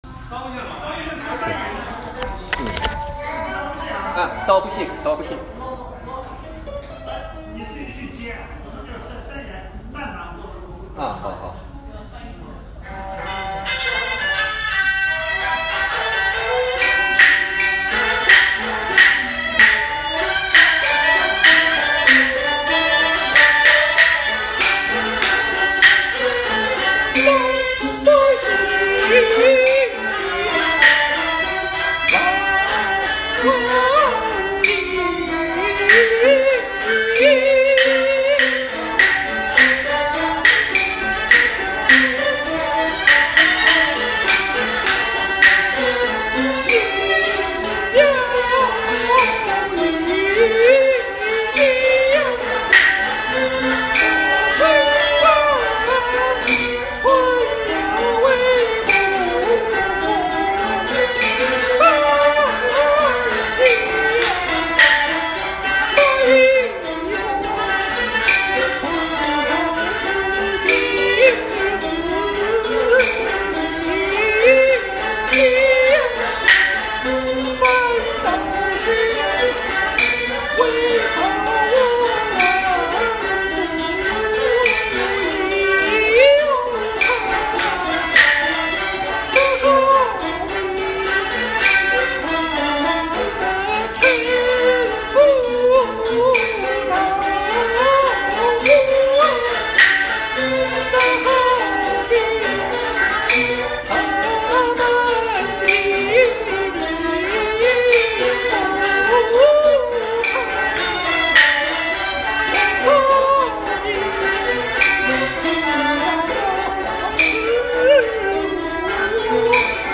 上次唱了“有贺后在金殿一声高骂”的导板回笼慢板部分，这次接着唱后面的快三眼部分。
这段快三眼花的功夫还不够，主要功夫花在慢板和散板唱段上了。
快三眼比慢板节奏快一倍左右，比较适合贺后此时由前面悲伤转为愤怒的心情。